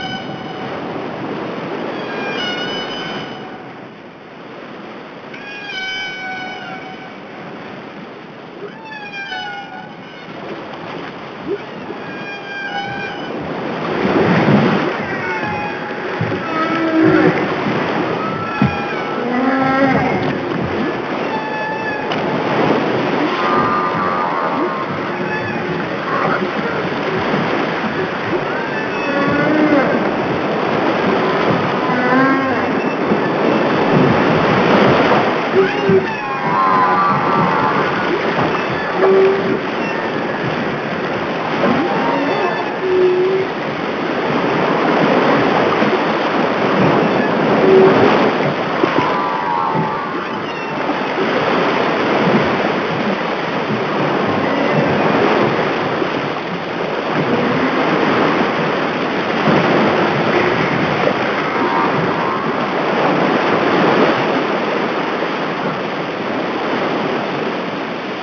Humpback
HUMPBACK.wav